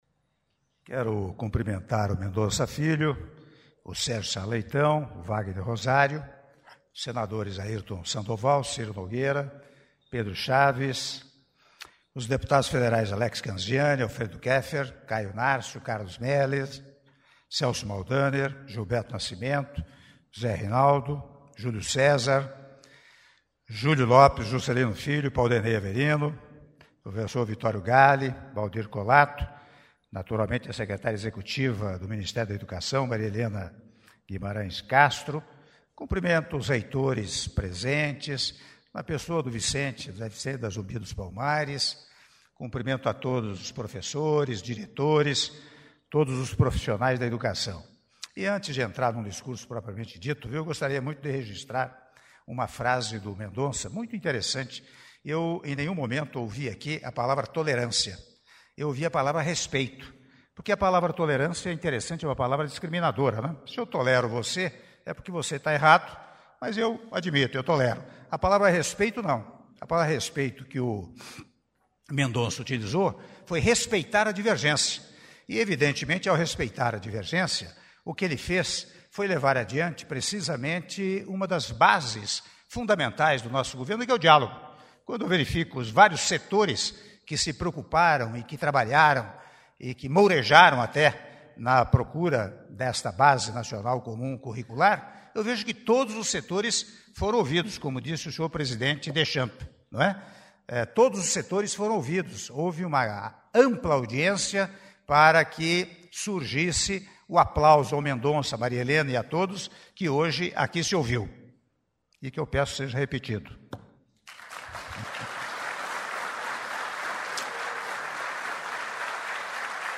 Áudio do discurso do Presidente da República, Michel Temer, durante cerimônia de Homologação da Base Nacional Comum Curricular - Palácio do Planalto (08min31s)